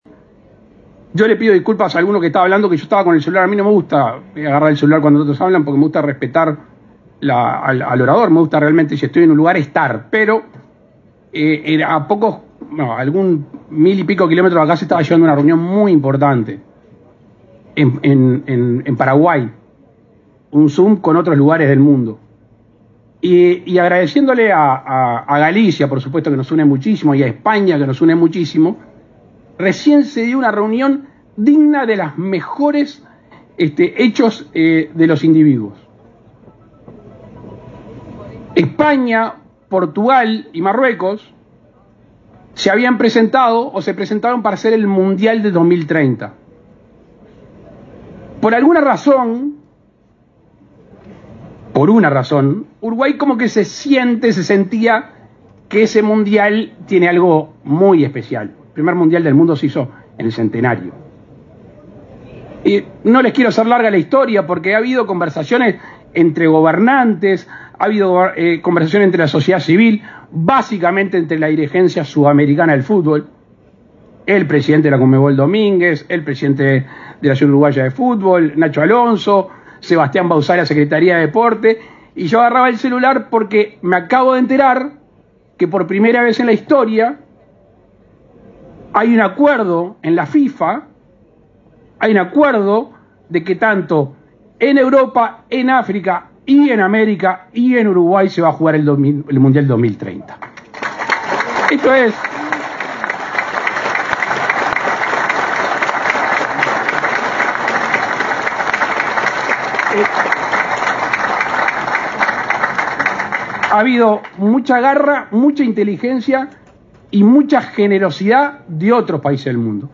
Palabras del presidente Luis Lacalle Pou
Este miércoles 4, el presidente de la República, Luis Lacalle Pou, se refirió en Salto, en oportunidad de la apertura de la feria Termatalia, al